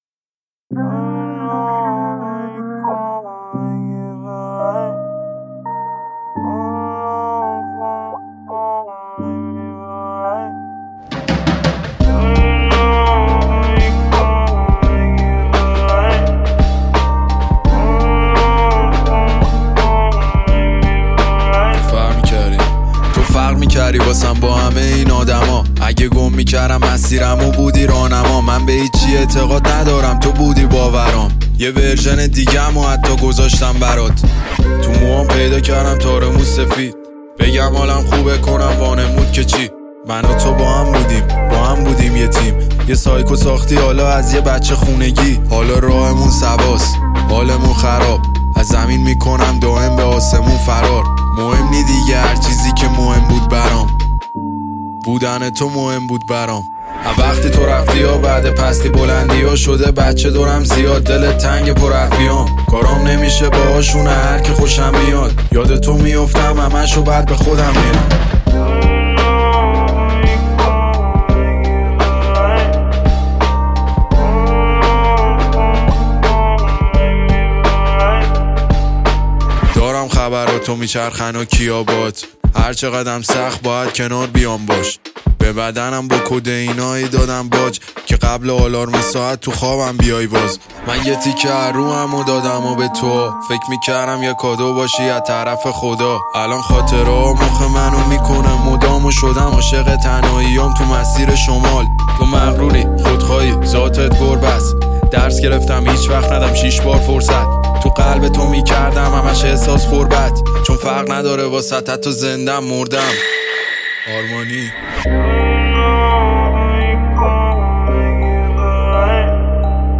ژانر: رپ.پاپ